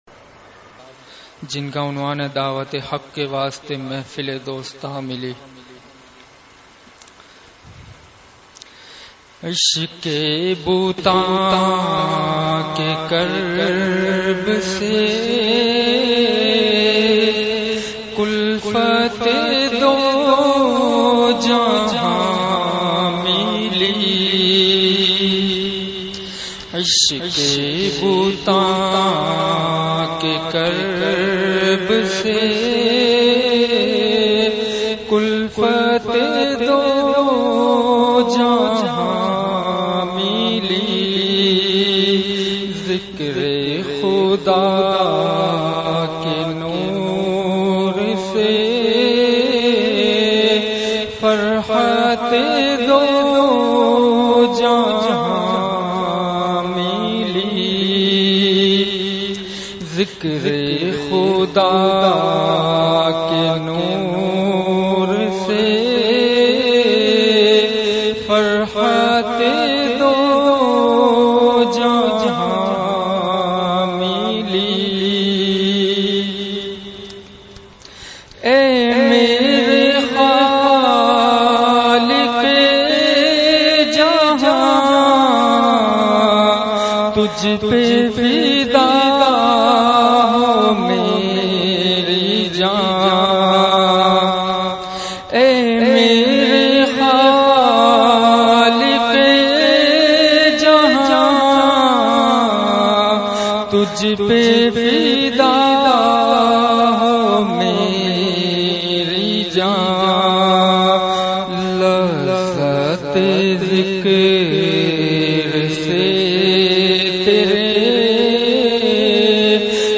حضرت کا مدرسہ اصحاب کہف گودھرا میں بیان